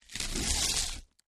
ho_fleshtear_03_hpx
Various parts of human flesh being ripped and torn. Mutilation, Body Dismemberment, Gore Tear, Flesh